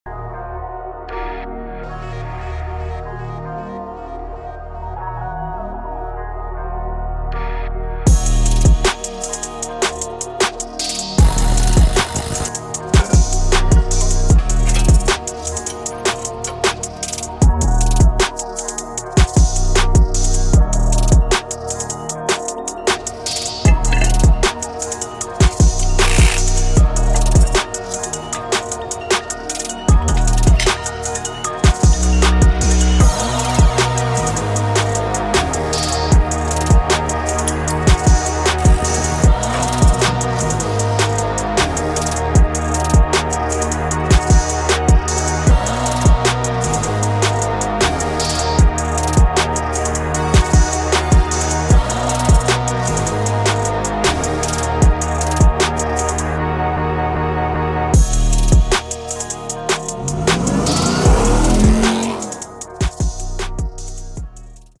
Perfect Power Brakes Set Sound Effects Free Download